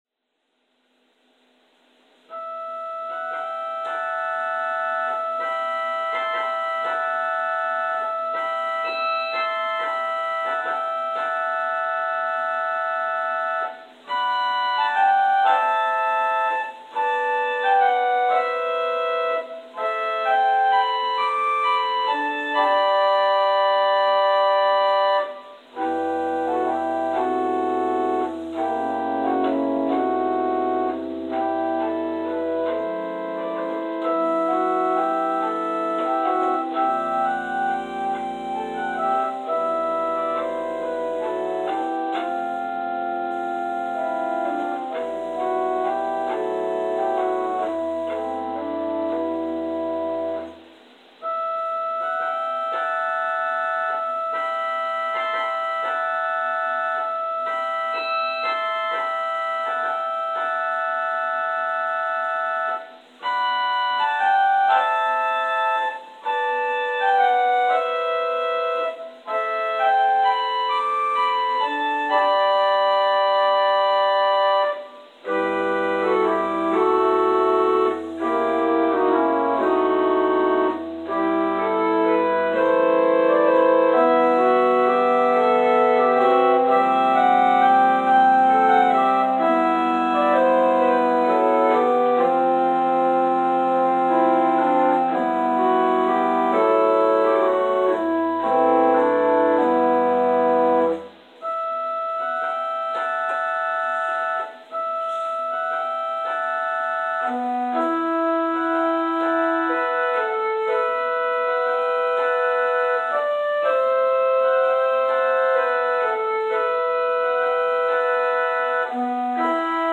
Prelude: “Morning Litany” – Lani Smith